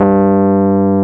Index of /90_sSampleCDs/Roland LCDP10 Keys of the 60s and 70s 2/PNO_Rhodes/PNO_73 Suitcase
PNO G1 F  00.wav